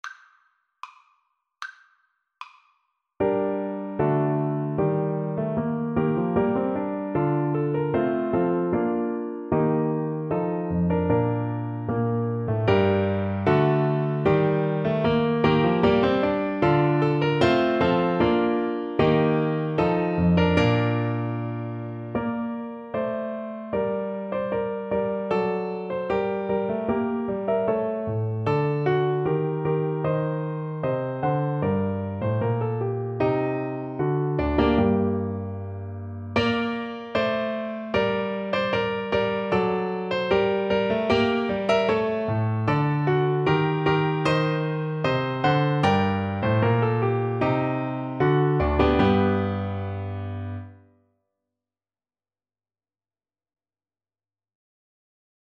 Play (or use space bar on your keyboard) Pause Music Playalong - Piano Accompaniment Playalong Band Accompaniment not yet available transpose reset tempo print settings full screen
G minor (Sounding Pitch) A minor (Clarinet in Bb) (View more G minor Music for Clarinet )
Steadily =c.76
Classical (View more Classical Clarinet Music)